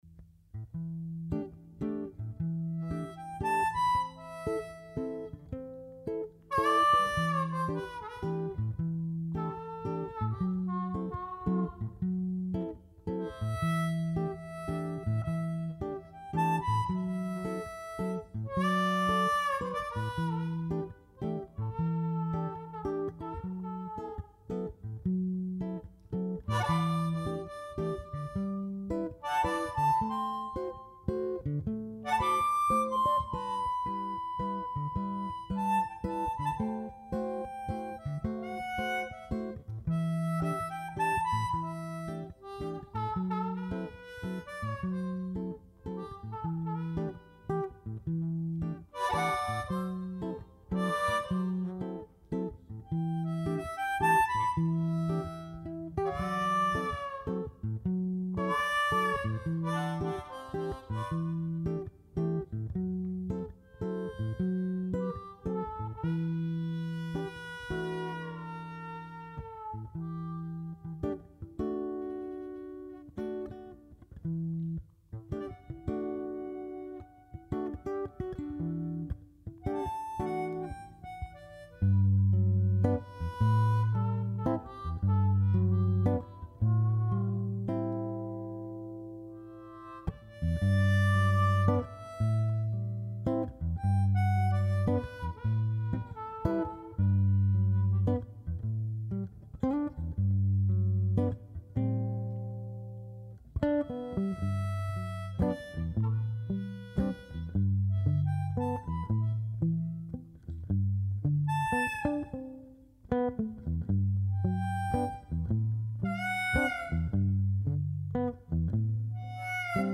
Largo [0-10] - - folk - guitare - country - slide - paysage